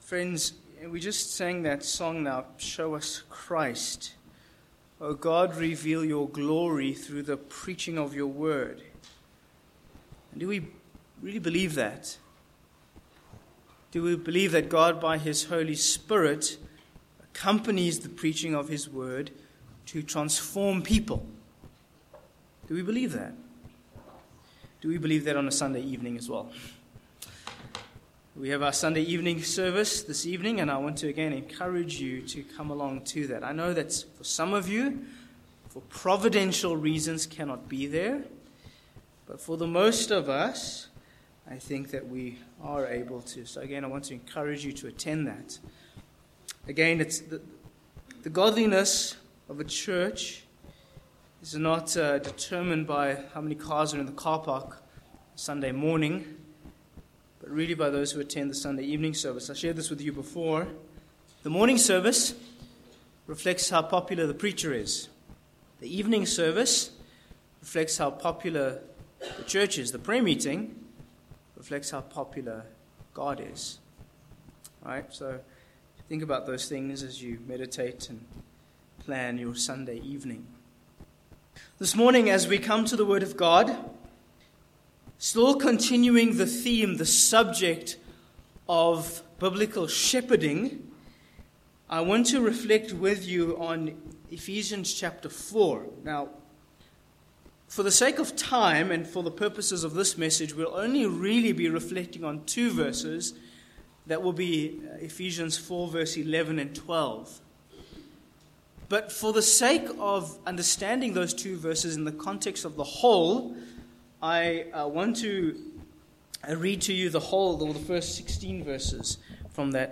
Passage: Ephesians 4:11-16 Sermon points: 1. The Gift of Godly Pastors v11